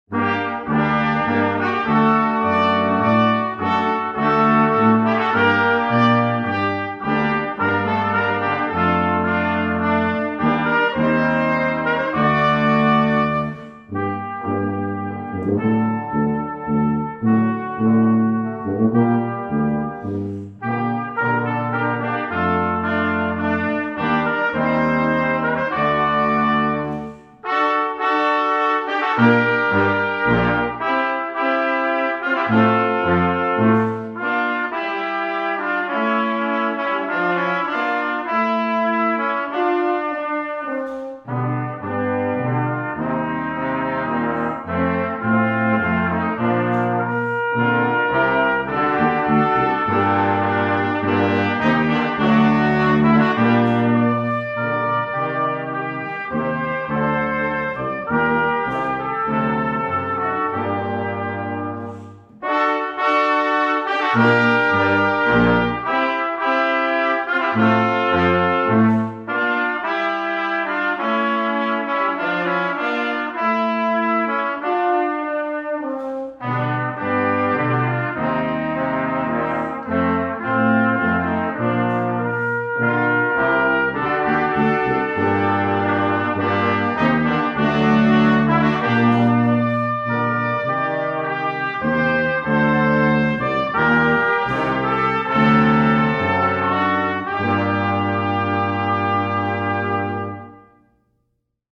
Wilmot Brass Quintet